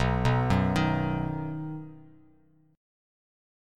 B Chord
Listen to B strummed